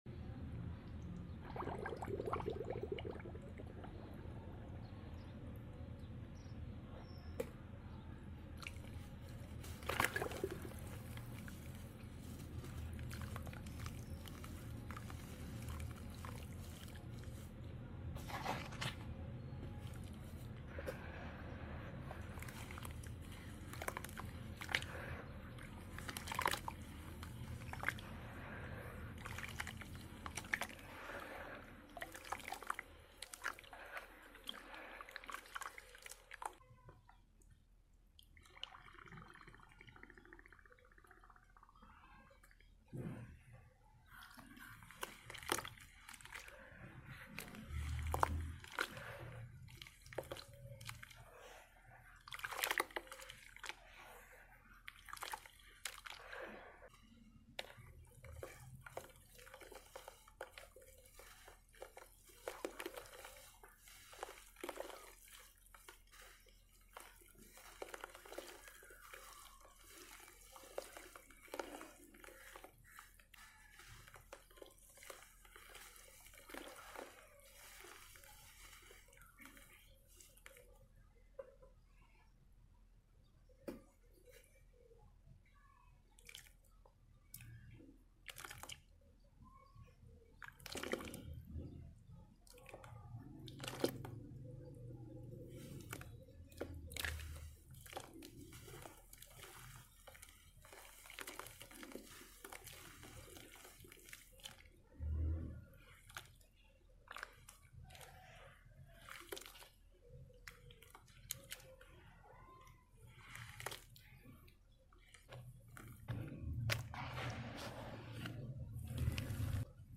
Upload By Everything ASMR